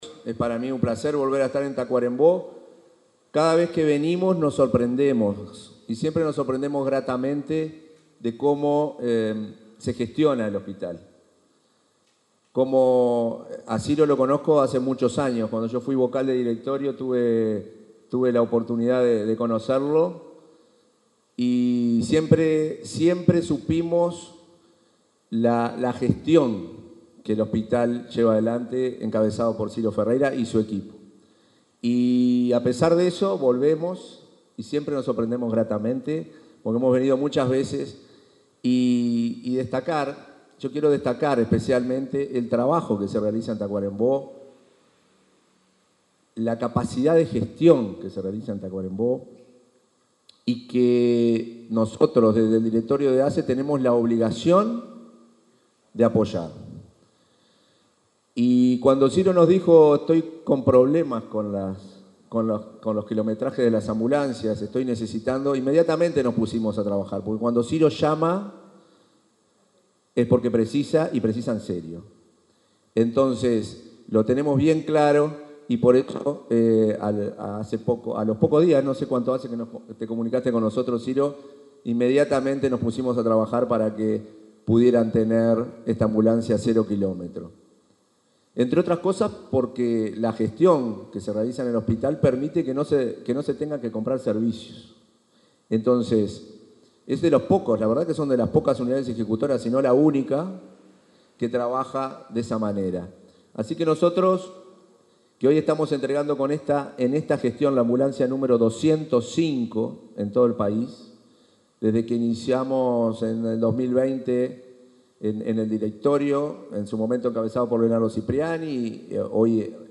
Palabras del presidente de ASSE en el hospital de Tacuarembó
Palabras del presidente de ASSE en el hospital de Tacuarembó 02/10/2024 Compartir Facebook X Copiar enlace WhatsApp LinkedIn Este miércoles 2, el presidente de la Administración de los Servicios de Salud del Estado (ASSE), Marcelo Sosa, participó del acto de entrega de una ambulancia para el hospital de Tacuarembó.